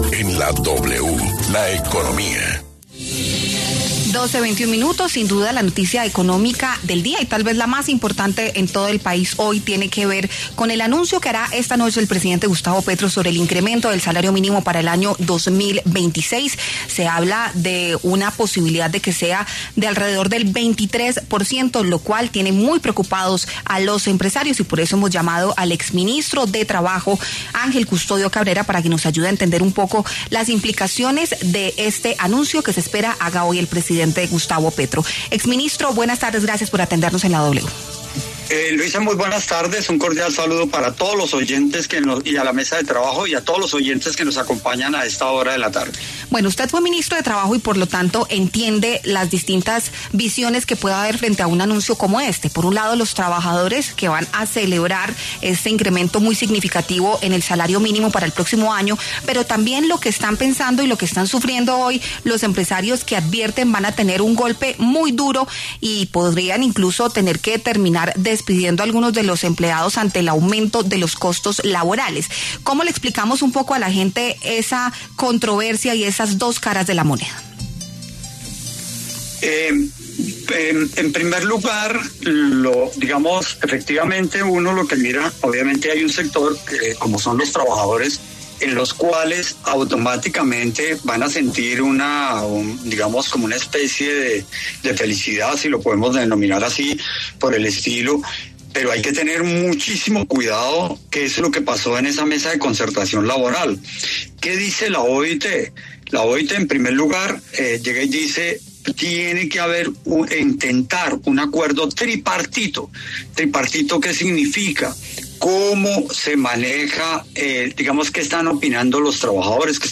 El exministro de Trabajo Ángel Custodio Cabrera aseguró en La W que un incremento del 23% en el salario mínimo aumentaría el costo que el Gobierno debe asumir en materia pensional.